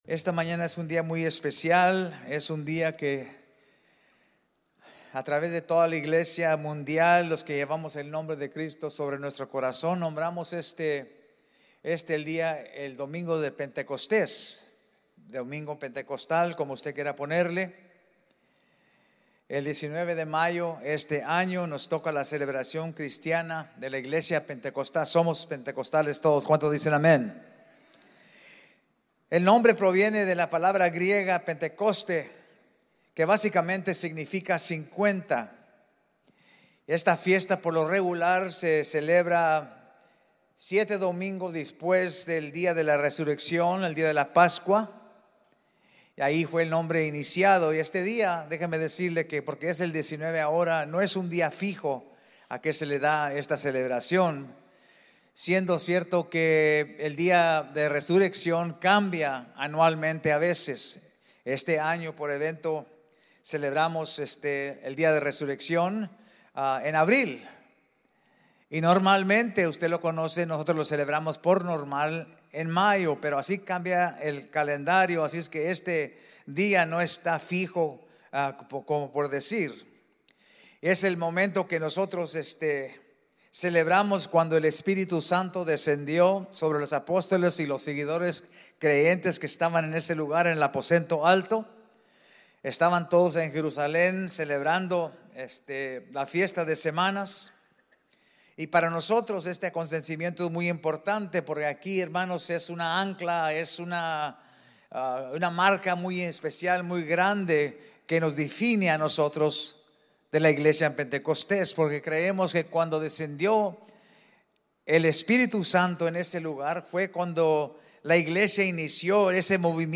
5-19-24-Sermon-Podcast-Esp.mp3